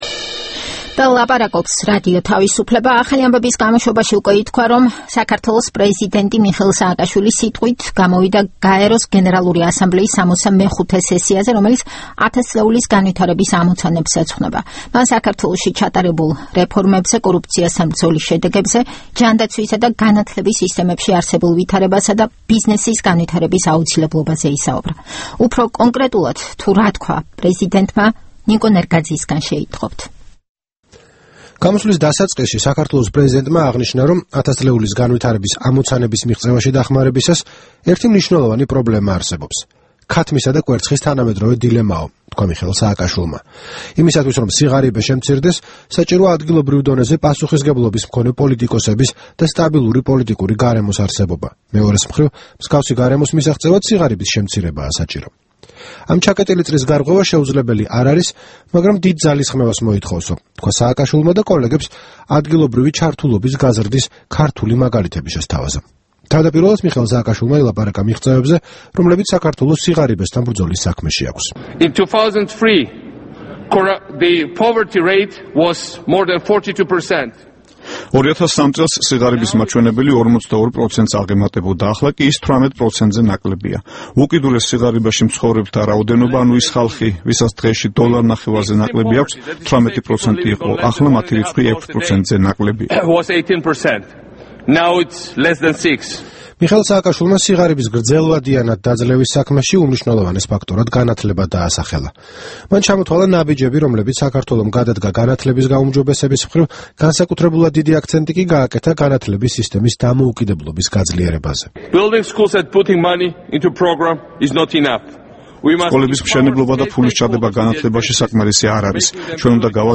გაეროს ტრიბუნიდან მიხეილ სააკაშვილმა საქართველოში გატარებულ რეფორმებზე ისაუბრა